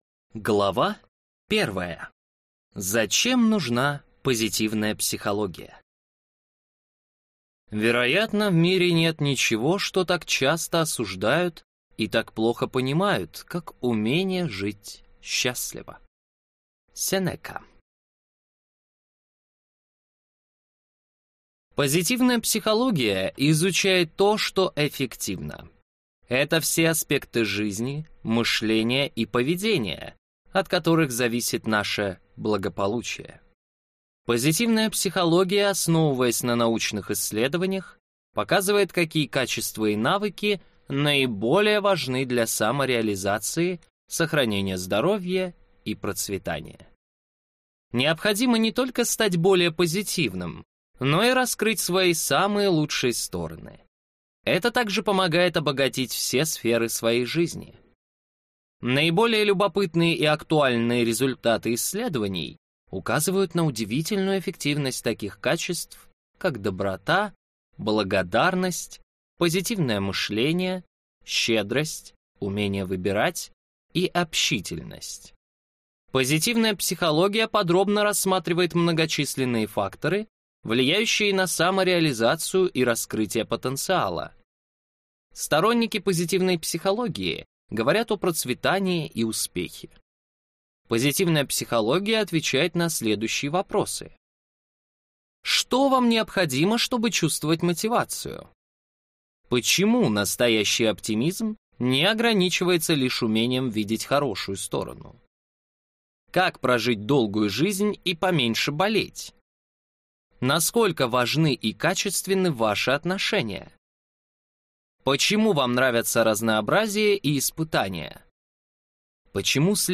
Аудиокнига Позитивная психология. Что делает нас счастливыми, оптимистичными и мотивированными | Библиотека аудиокниг